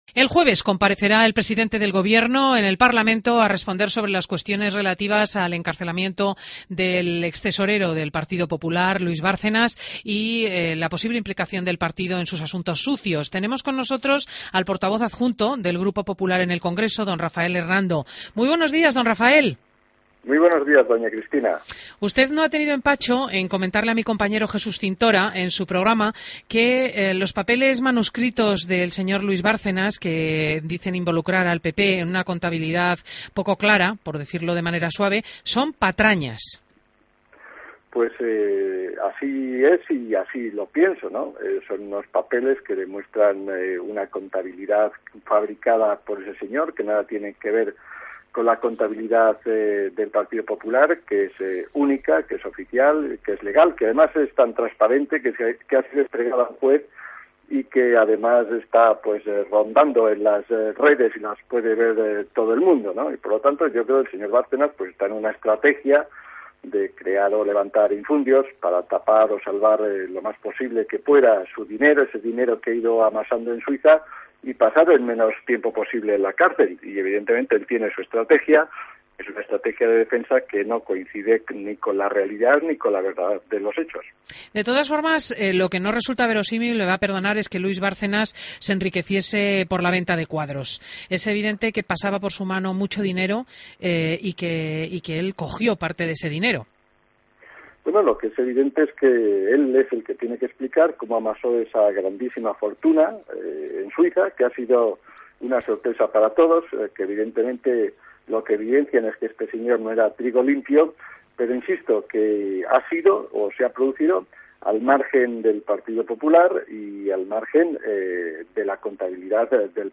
AUDIO: Entrevista a Rafael Hernando en Fin de Semana